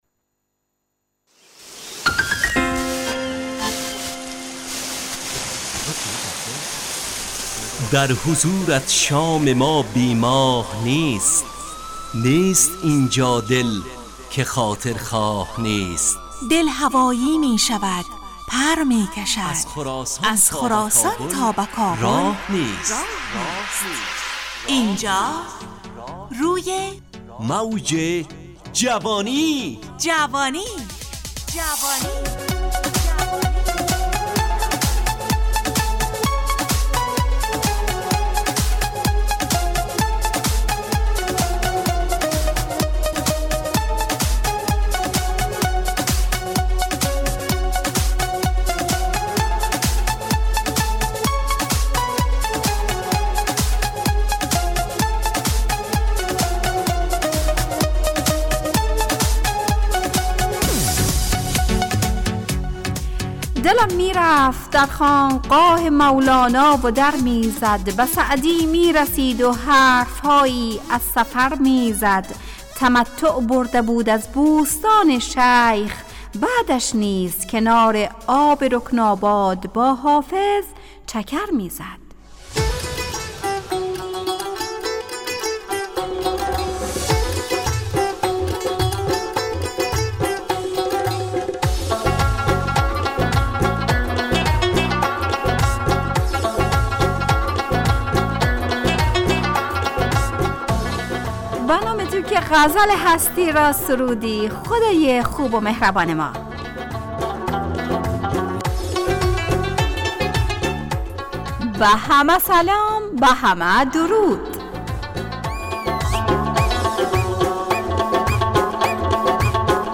همراه با ترانه و موسیقی مدت برنامه 55 دقیقه . بحث محوری این هفته (اگر کتاب نخوانیم ...)